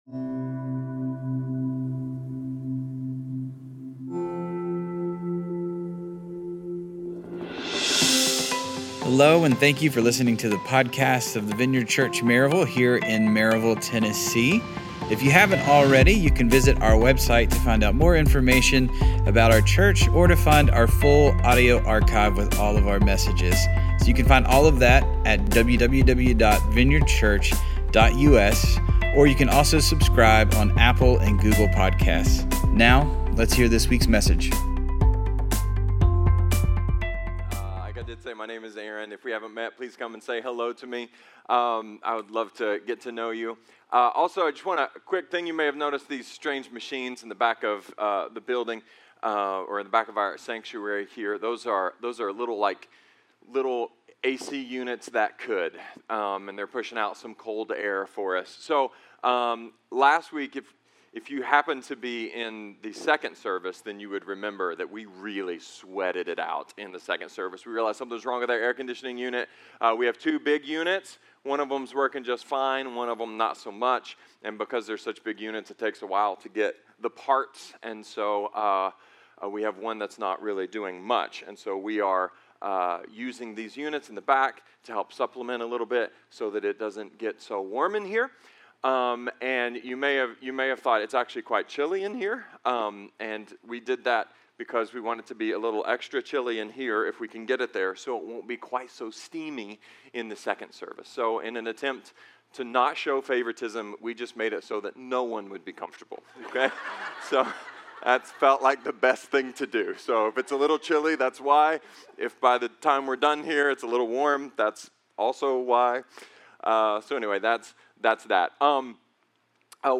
A sermon about a simple (but difficult) method for fighting the Devil and his lies.